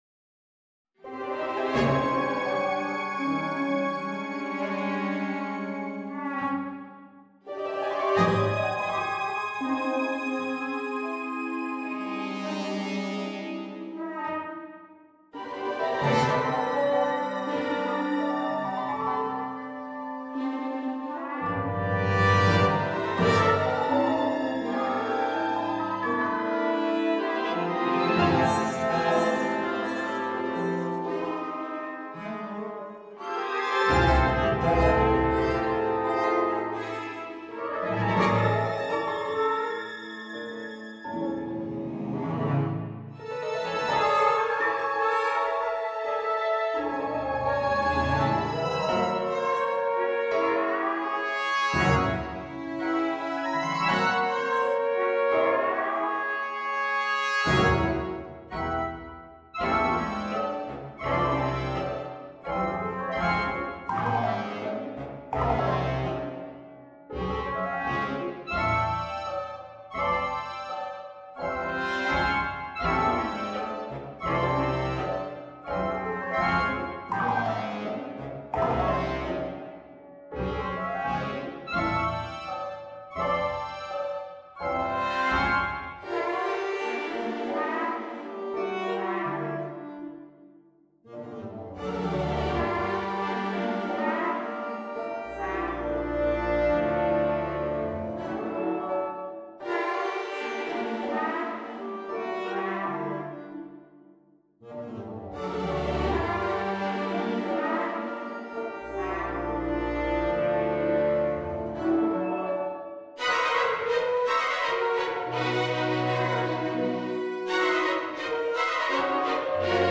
for alto flute, tenor trombone, violoncello and orchestra
Though prominently featured and near-constantly playing, the three “soloists” never perform the usual virtuosic runs over a bland forgettable accompaniment; instead, they act as protagonists guiding the ensemble through a slowly changing, constantly variegated musical color field.